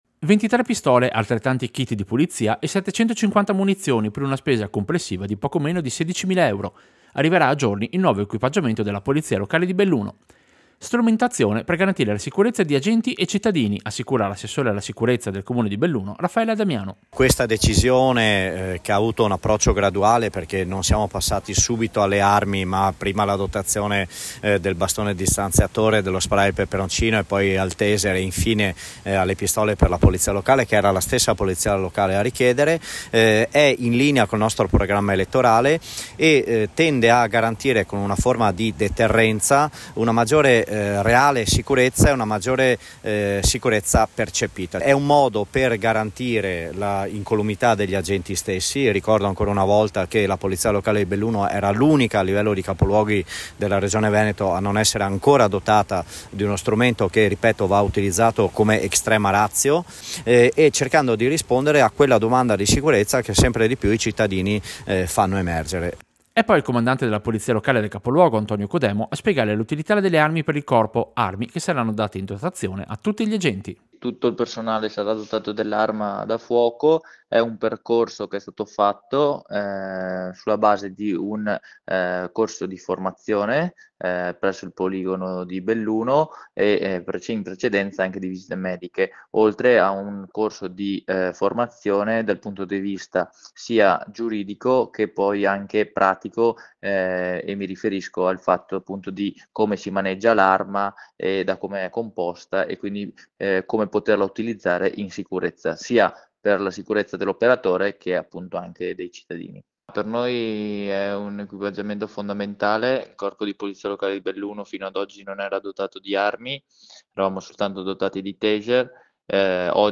Servizio-Pistole-Polizia-Locale-Belluno.mp3